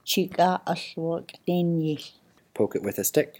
Poke /ghu…yił/